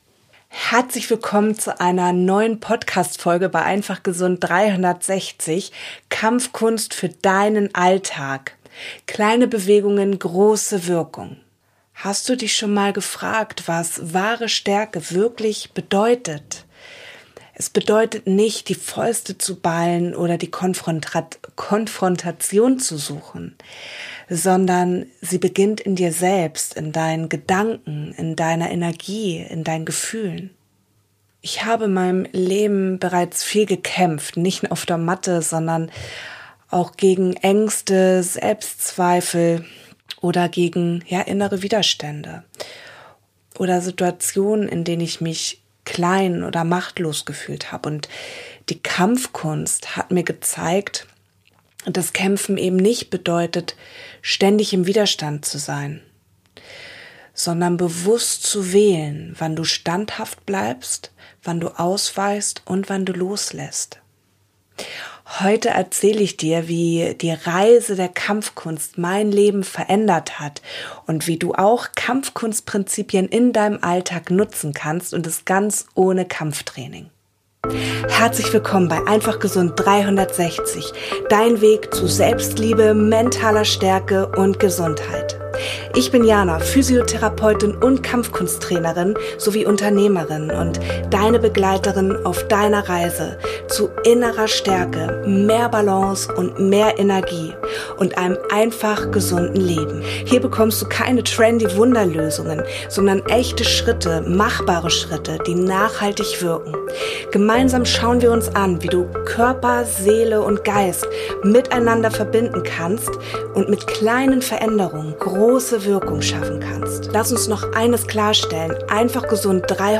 Audition Template: 24 Mono tracks routed to a Stereo Master. 44.1k, 24 bit, Stereo Master.